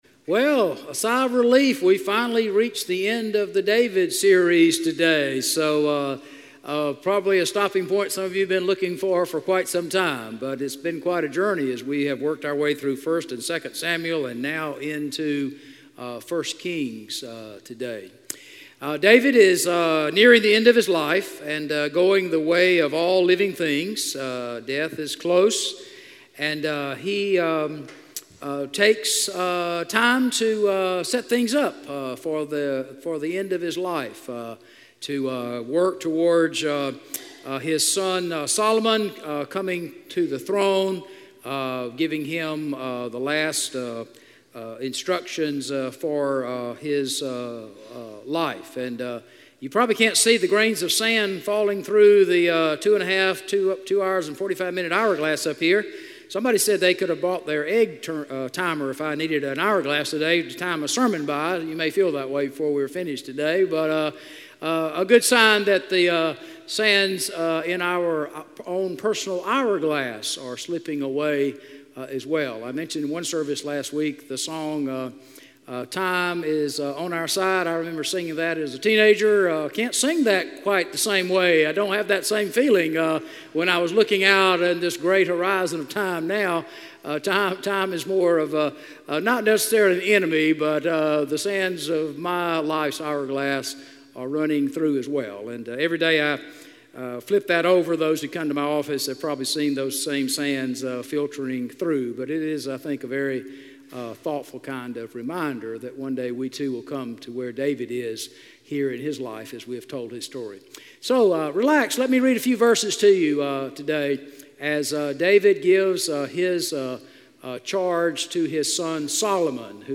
A message from the series "The Story of David."